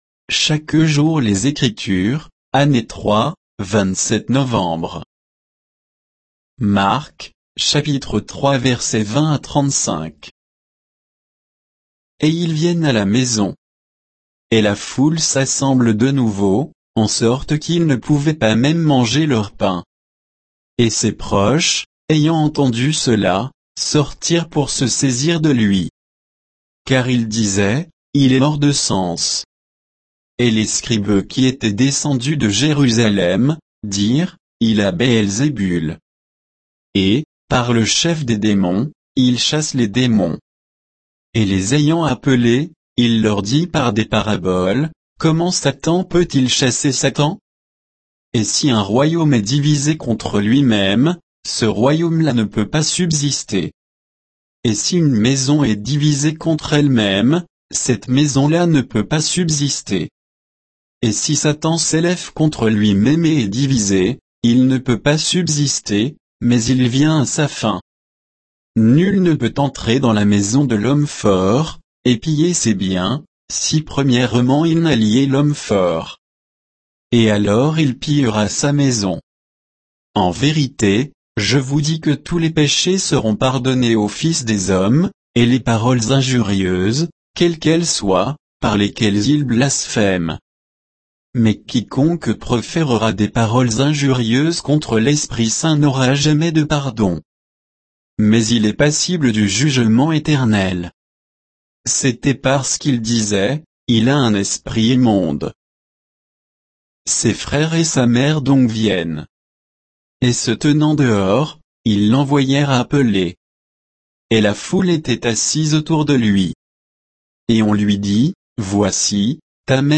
Méditation quoditienne de Chaque jour les Écritures sur Marc 3, 20 à 35